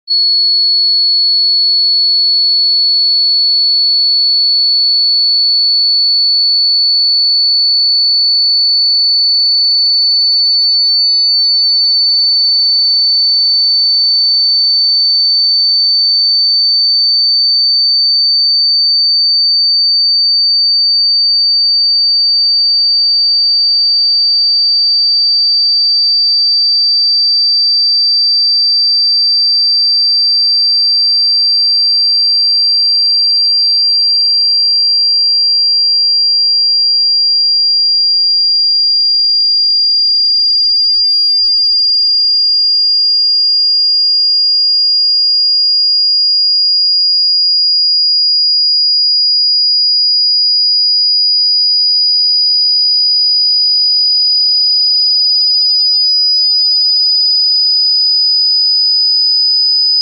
Exploring 10k Hz: Precision in sound effects free download